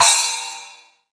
BR Splash.WAV